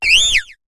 Cri de Trompignon dans Pokémon HOME.